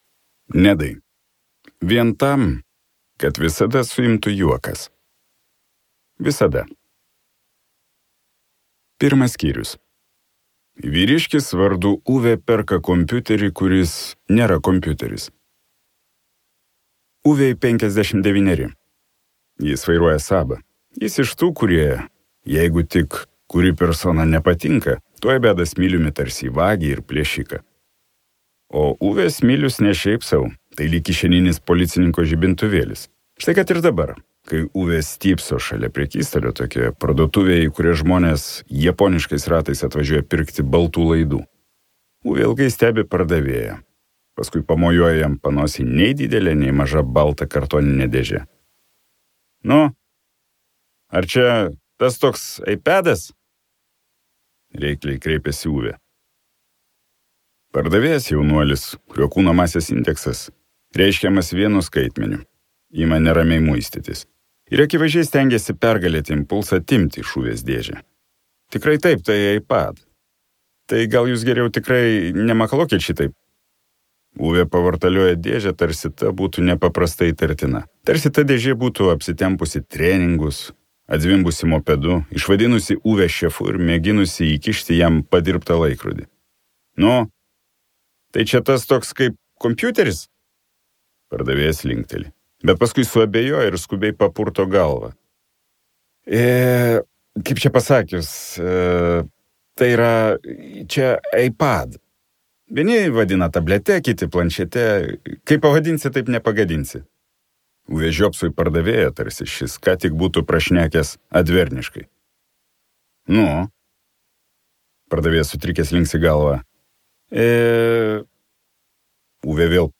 Gyveno kartą Uvė | Audioknygos | baltos lankos
Balsas: Jokūbas Bareikis